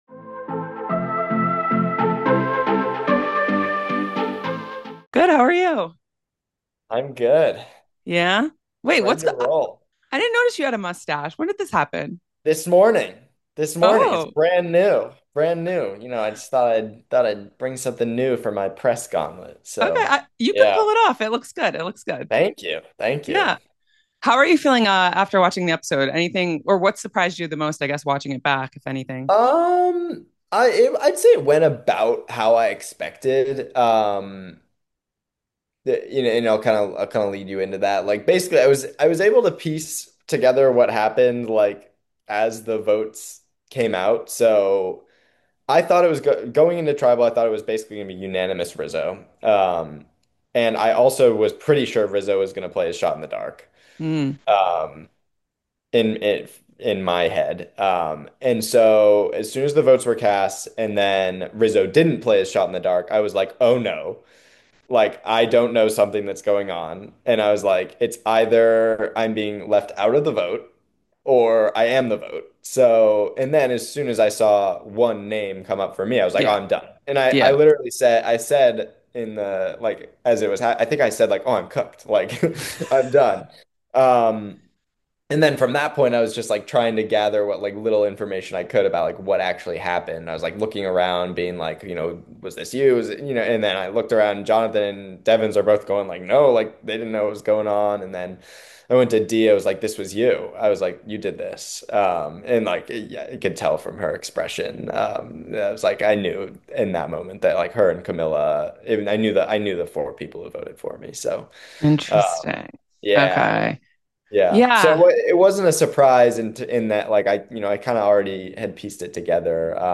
Survivor 50 Exit Interview: 7th Player Voted Out